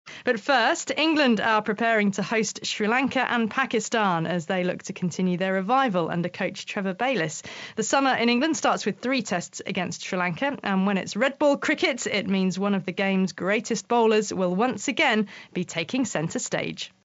【英音模仿秀】凤凰涅槃 浴火重生 听力文件下载—在线英语听力室